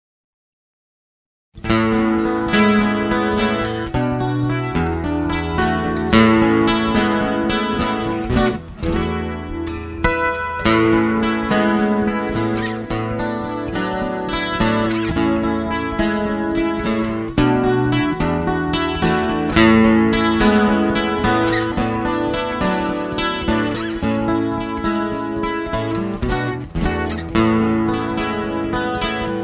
Instrumental Tracks.
▪ The full instrumental track